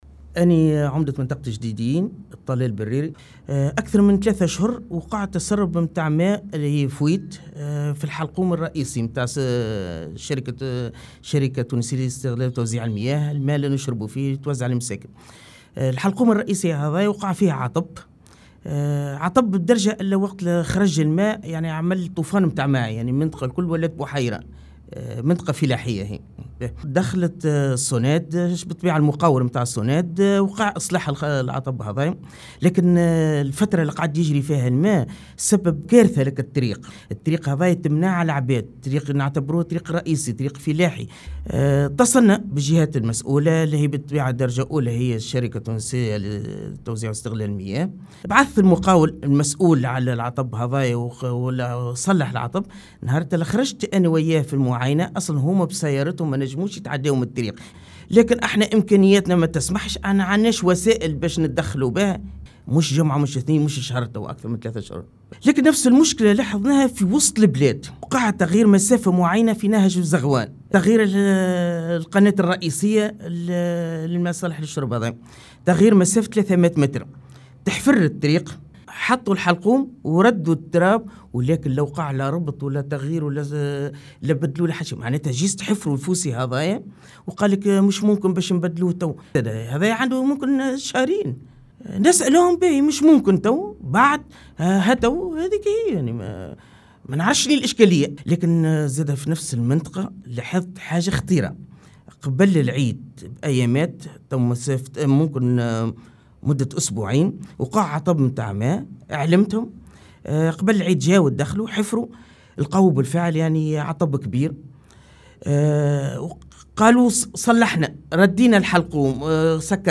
تصريح العمدة